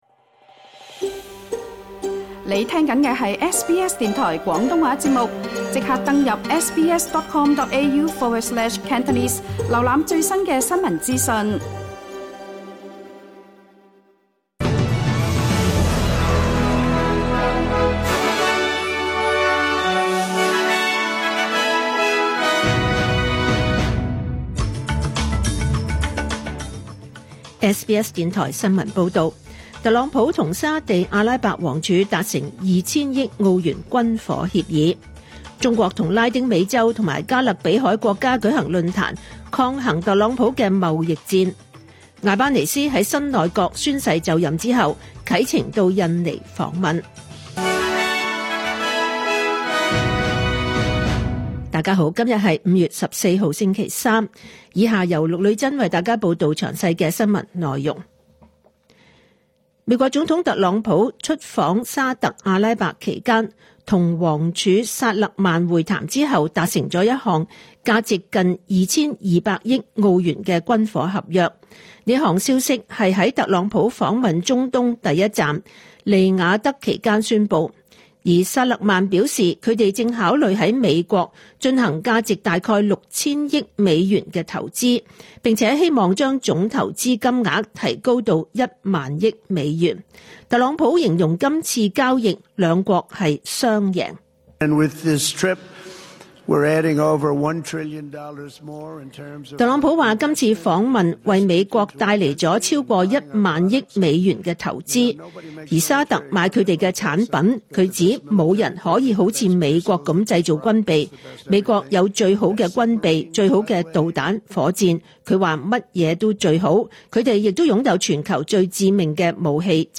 2025 年 5 月 14 日 SBS 廣東話節目詳盡早晨新聞報道。